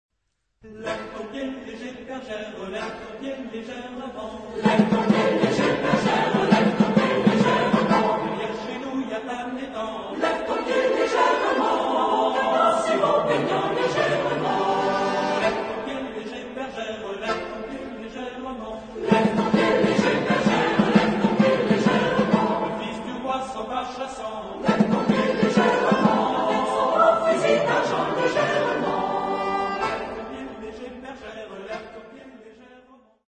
Genre-Style-Form: Secular ; Popular ; Song with repetition
Mood of the piece: light
Type of Choir: SATB  (4 mixed voices )
Soloist(s): Ténor (1)  (1 soloist(s))
Tonality: A aeolian